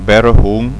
Prononcer "Bèrehoun".